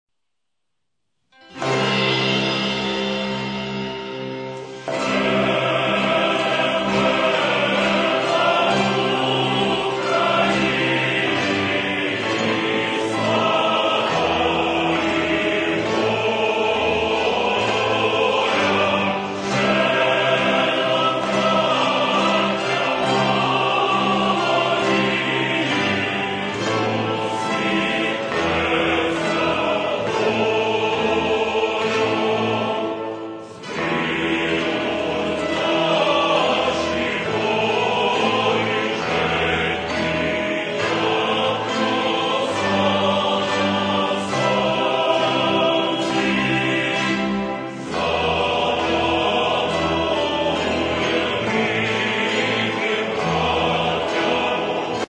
Народна (248)